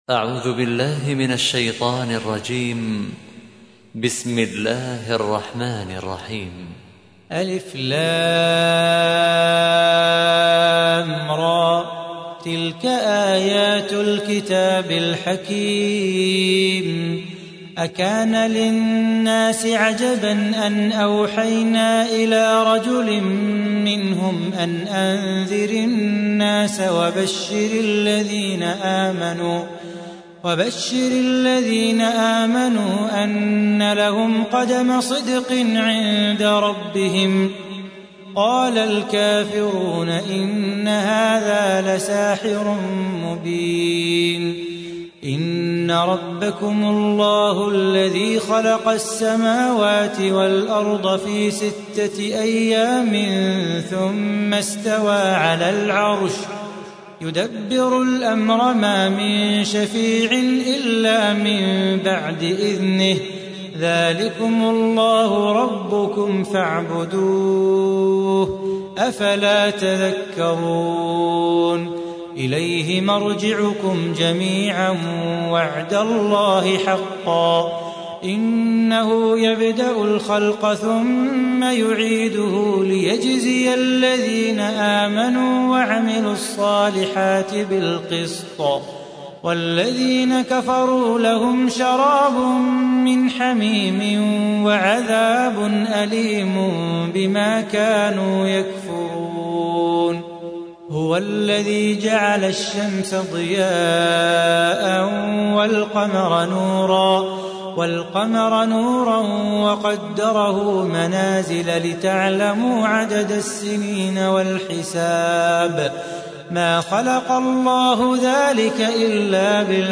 تحميل : 10. سورة يونس / القارئ صلاح بو خاطر / القرآن الكريم / موقع يا حسين